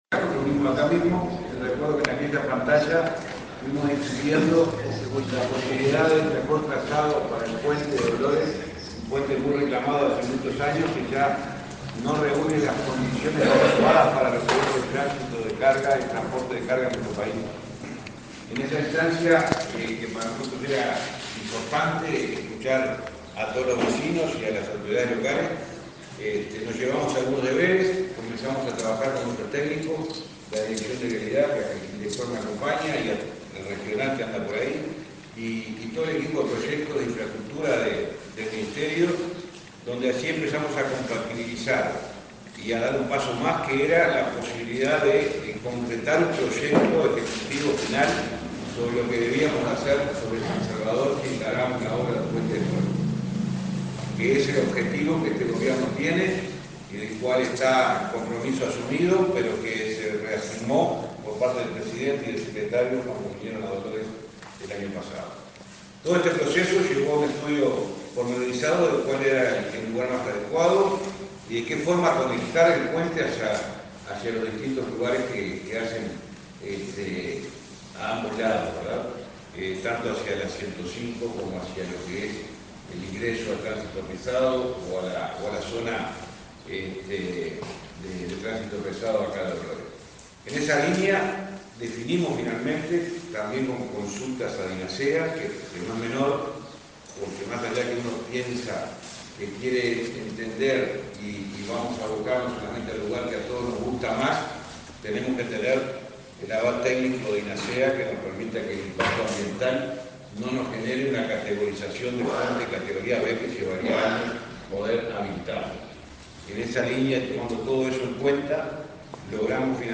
Palabras del ministro de Transporte y Obras Públicas, José Luis Falero
Falero acto.mp3